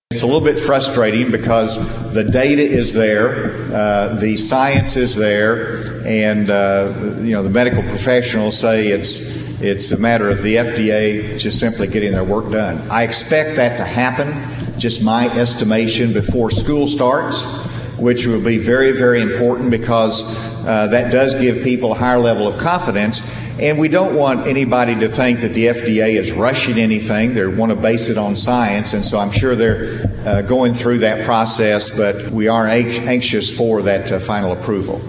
Gov. Asa Hutchinson brought his “Community COVID Conversations” event to Mountain Home Monday, holding an hour-long town hall-style event inside the Vada Sheid Community Development Center on the campus of Arkansas State University-Mountain Home.